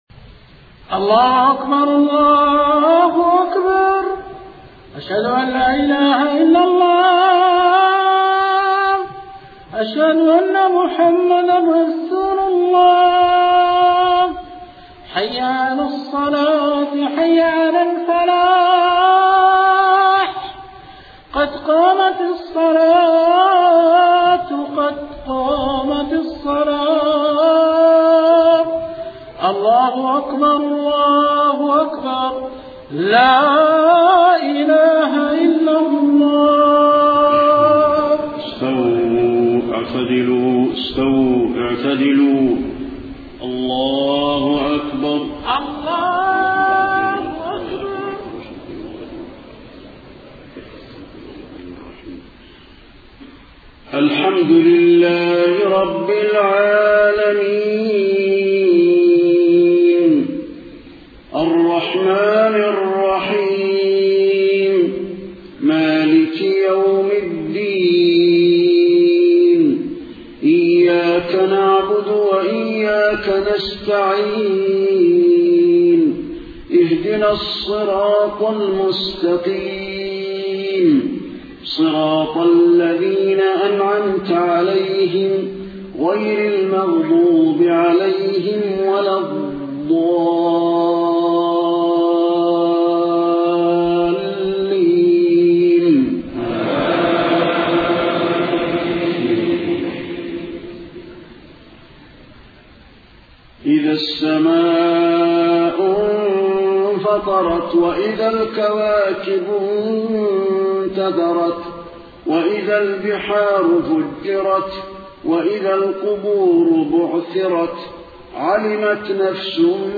صلاة الجمعة 28 صفر 1431هـ سورة الانفطار كاملة > 1431 🕌 > الفروض - تلاوات الحرمين